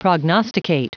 Prononciation du mot prognosticate en anglais (fichier audio)
Prononciation du mot : prognosticate